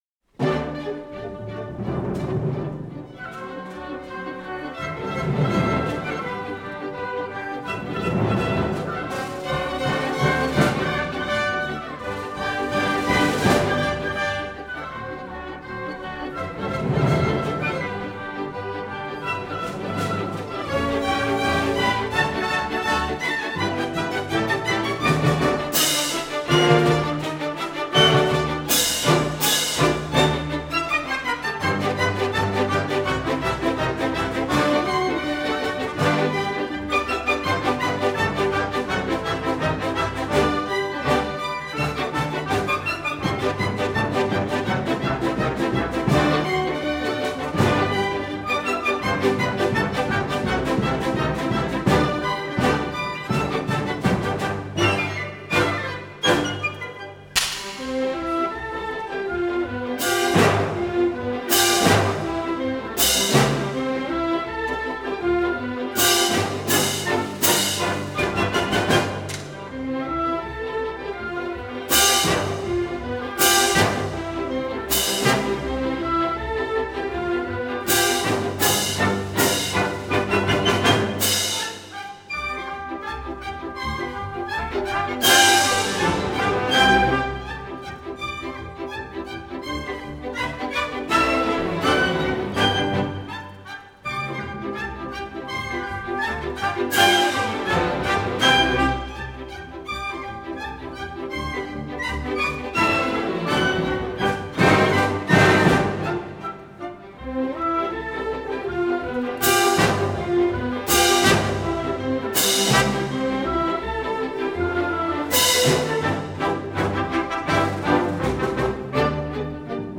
LNSO orķestris, izpildītājs
Polkas
Aplausi !
Jautrs
Enerģisks
Siguldas estrāde